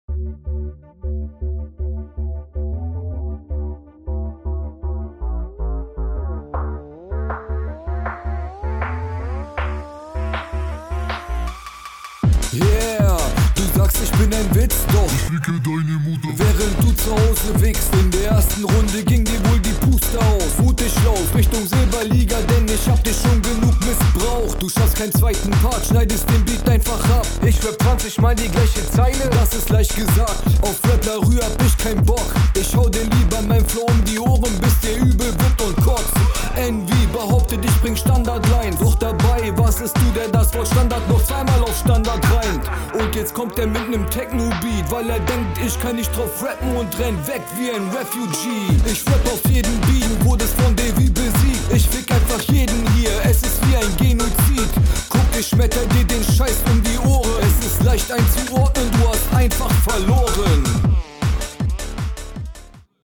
Technik und mische wieder sehr stabil, auch die Genozid line fand ich ganz nice.